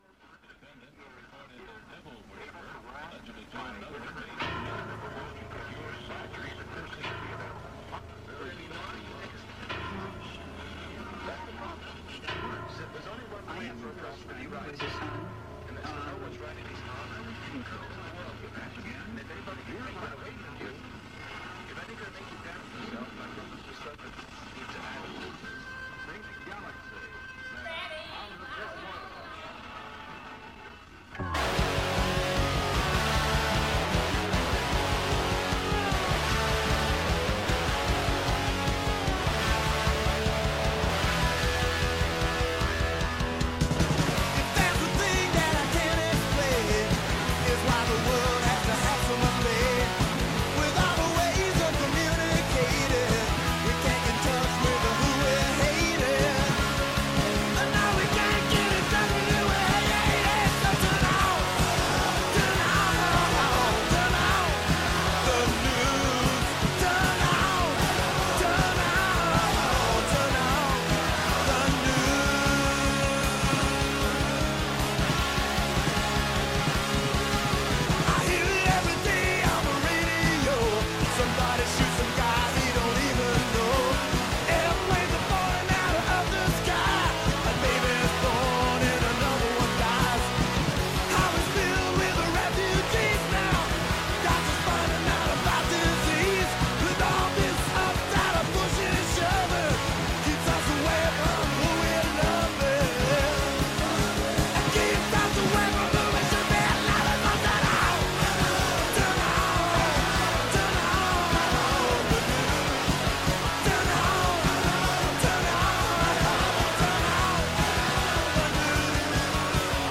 Turn On the News is a daily radio news program fea...